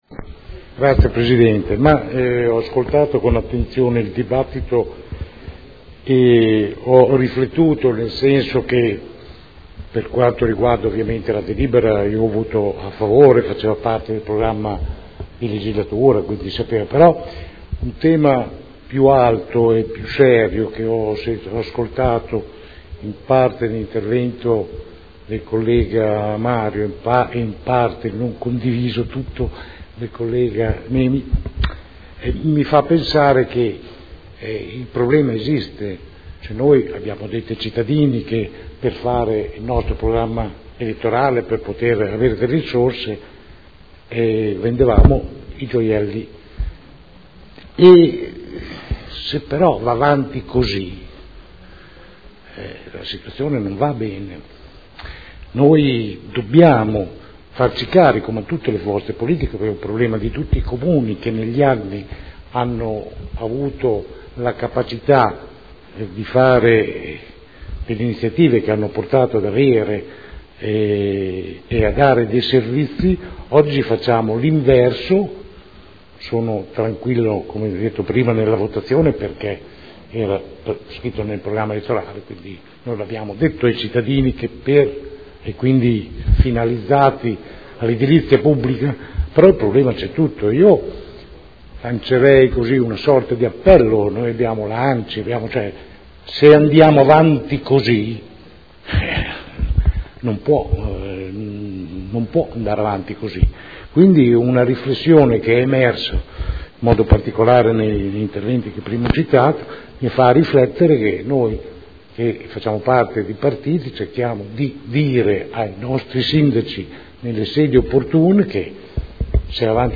Francesco Rocco — Sito Audio Consiglio Comunale